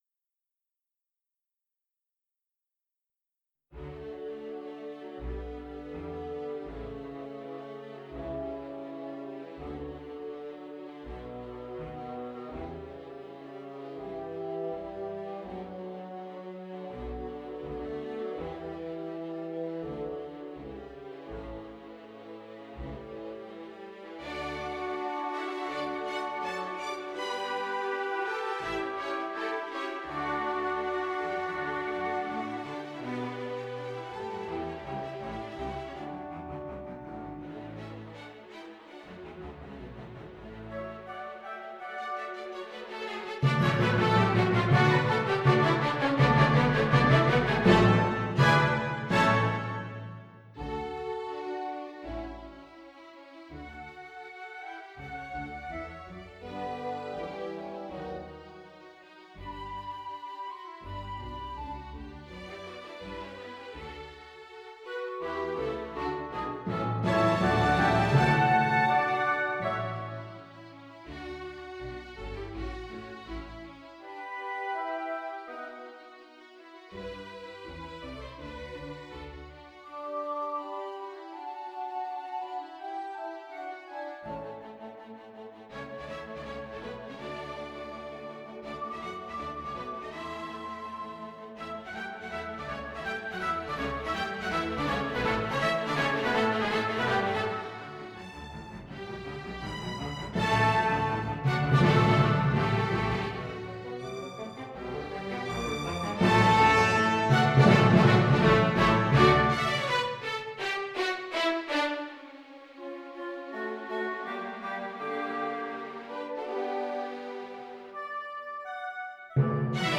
Konzertes für Violine, Cello und Orchester
Adagio Variation